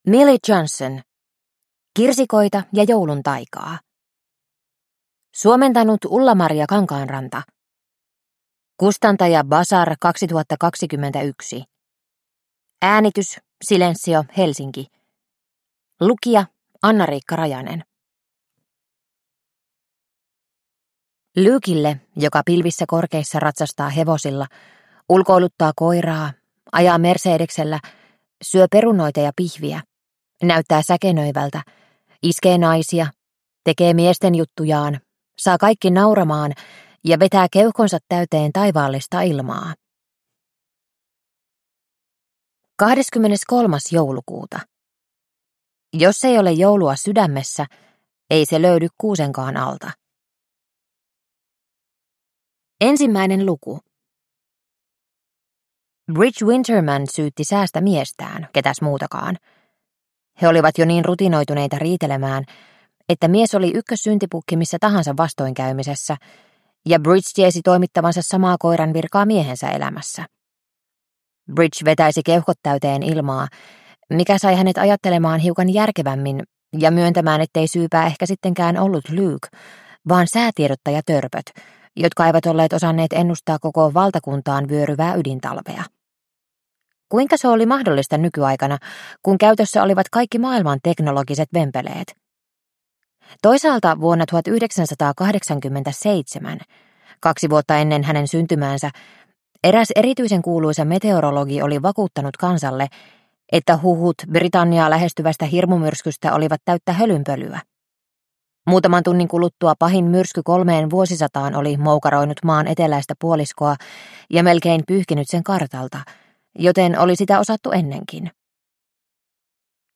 Kirsikoita ja joulun taikaa – Ljudbok – Laddas ner